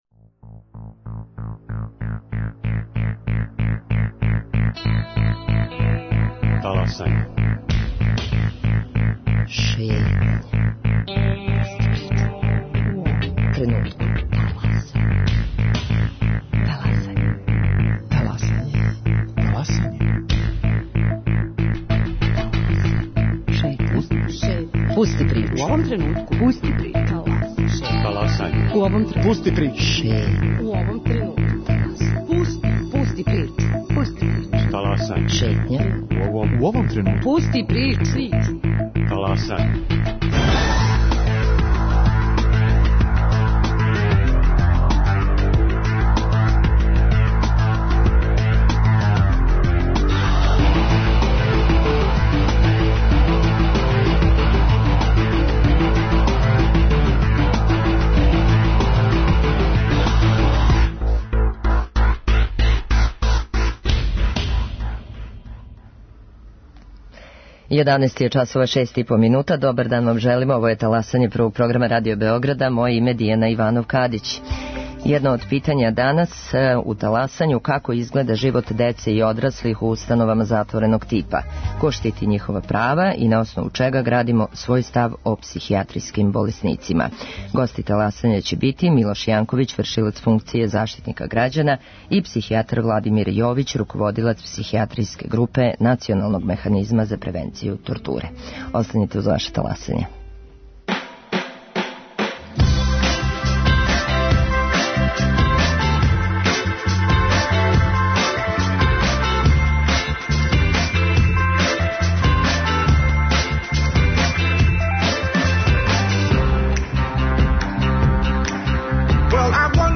Гости Таласања су Милош Јанковић, вршилац функције Заштитника грађана